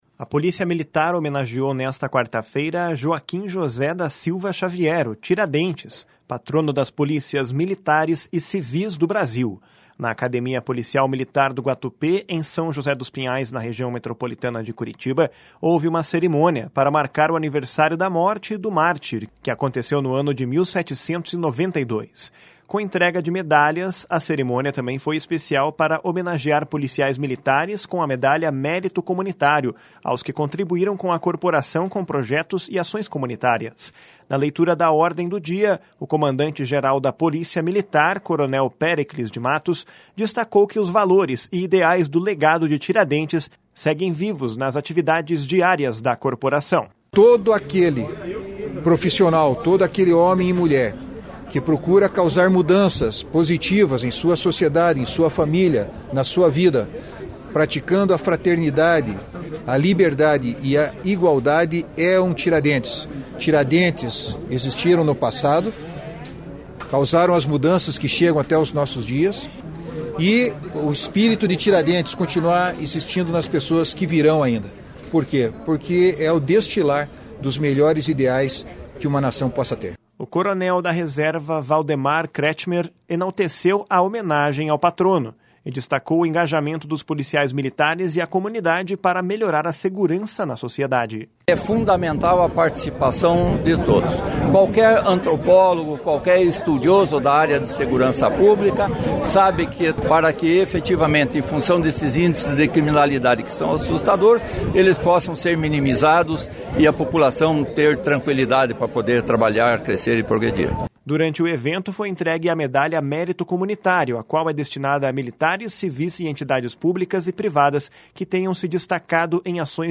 Na leitura da Ordem do Dia, o comandante-geral da Polícia Militar, coronel Péricles de Matos, destacou que os valores e ideais do legado de Tiradentes seguem vivos nas atividades diárias da corporação.